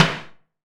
SNARE 098.wav